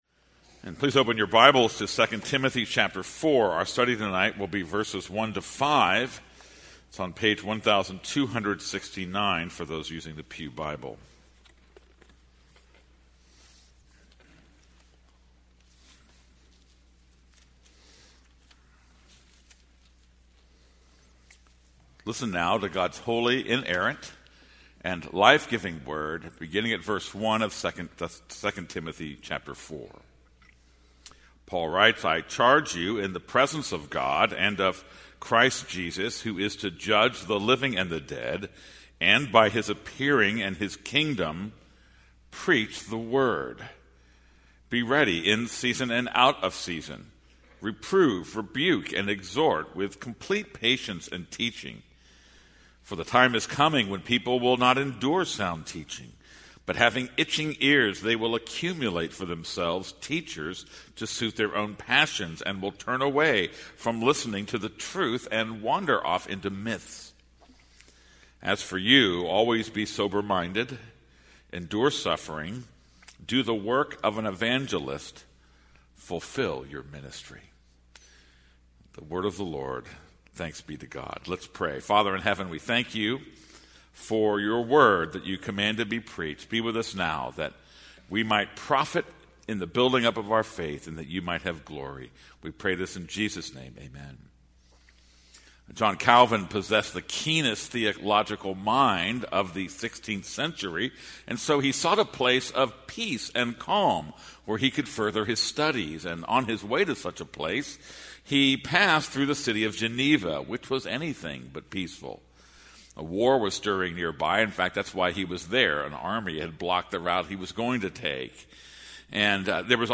This is a sermon on 2 Timothy 4:1-5.